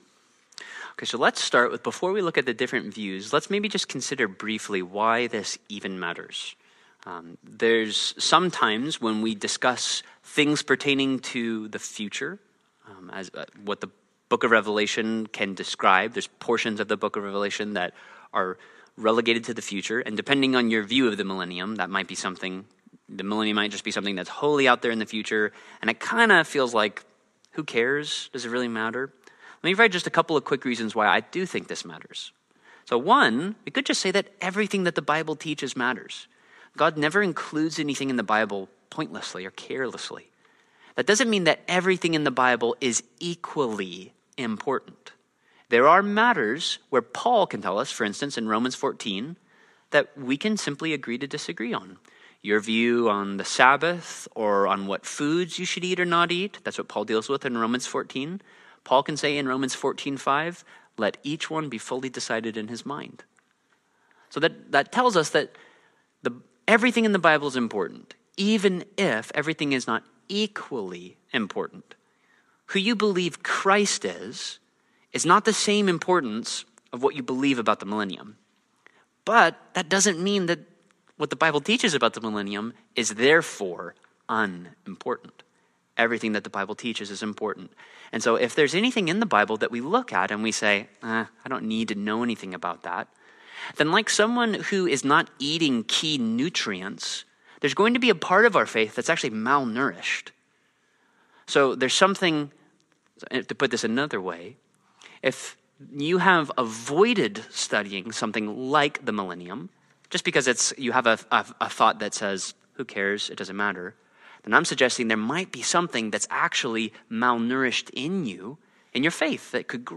Download Download Reference Revelation 11 Sermon Notes Rev 11.pdf Apocalypse Now (Revelation) Apocalypse Now #13: The Great White Throne What Does Revelation Teach Us About the Final Judgment?